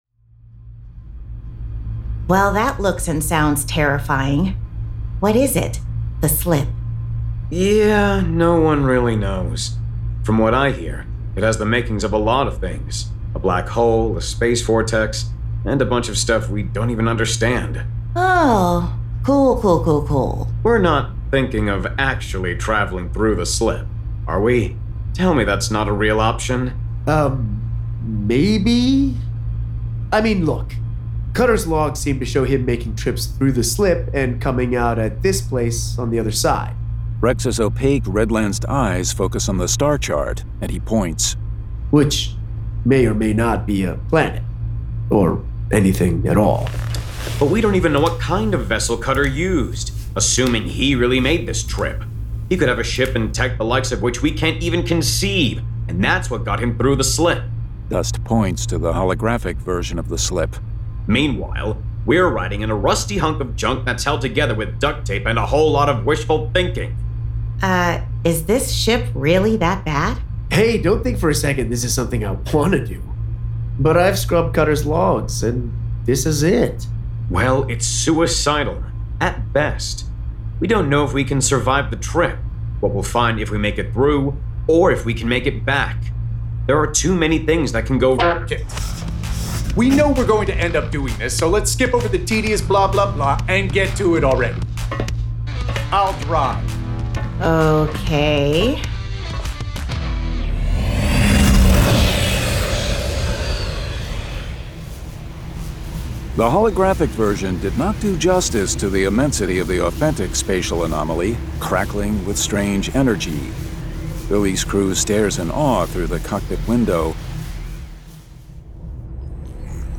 Full Cast. Cinematic Music. Sound Effects.
[Dramatized Adaptation]
Adapted directly from the graphic novel and produced with a full cast of actors, immersive sound effects and cinematic music.